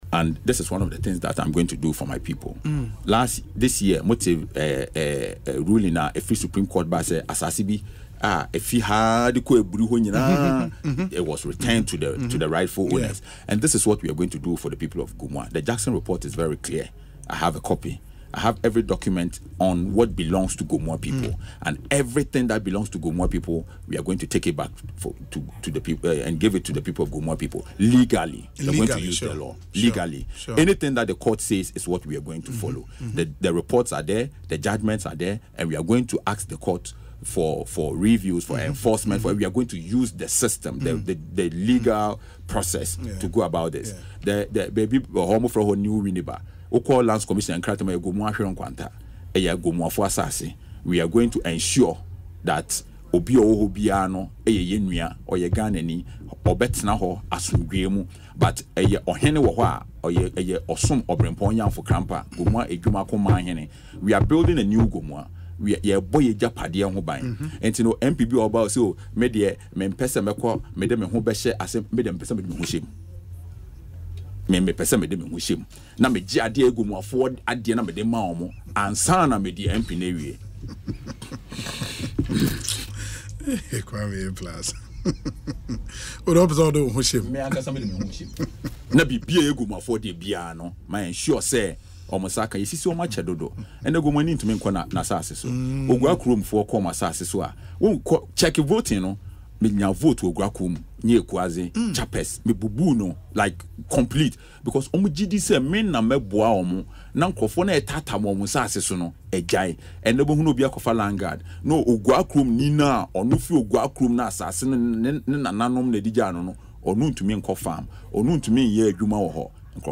In an interview on Asempa FM’s Ekosii Sen, programme Wednesday, he vowed to reclaim all lands to serve justice for his people.